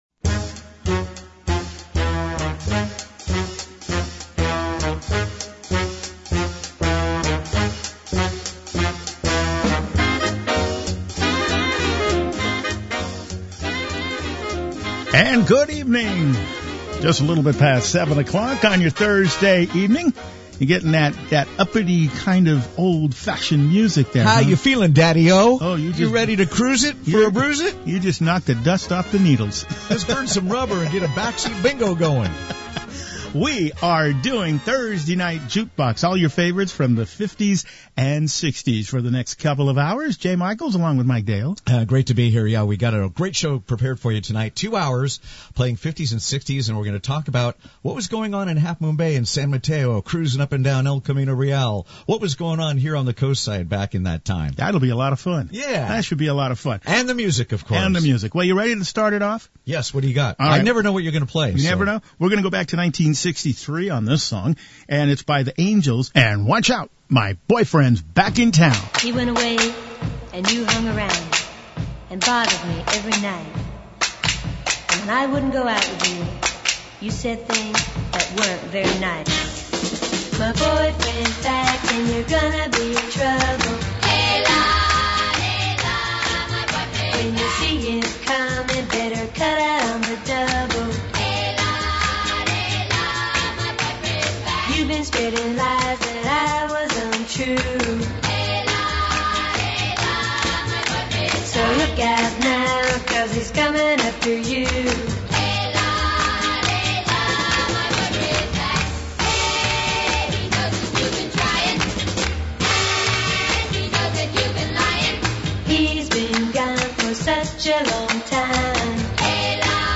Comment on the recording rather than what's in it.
Now you can hear our Thursday night live broadcasting if you didn’t catch it on the air.